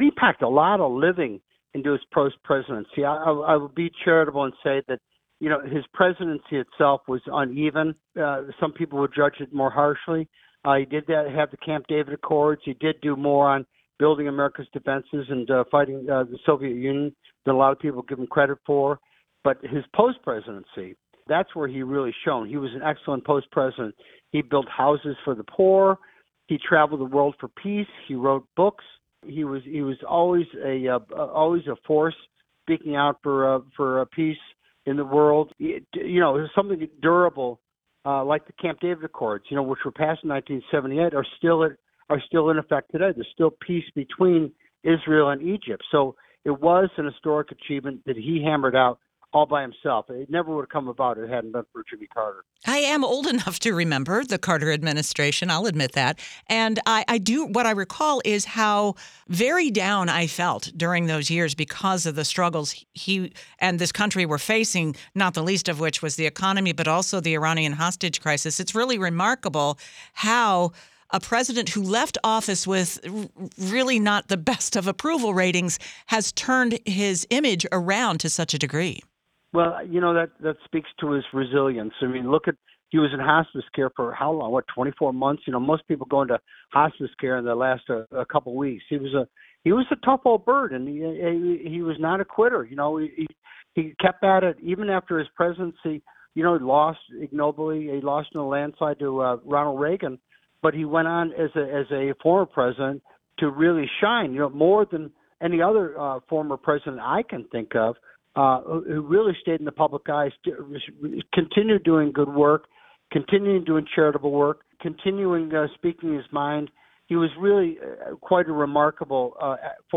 WTOP spoke with historians, political reporters and those who personally knew Carter as the nation mourned his death.